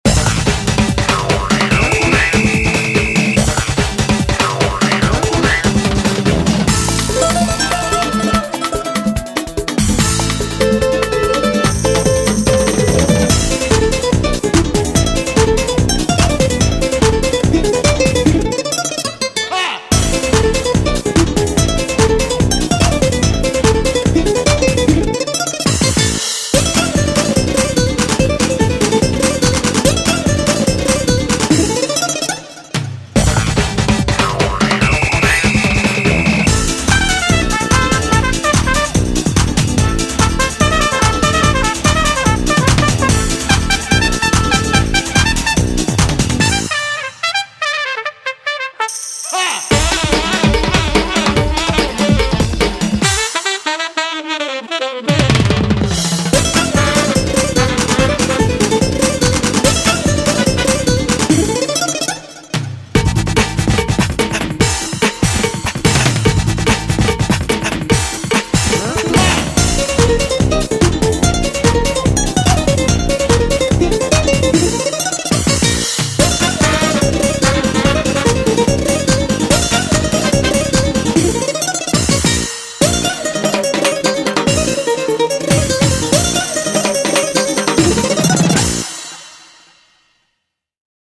BPM145
Comments[SPANISH GROOVE]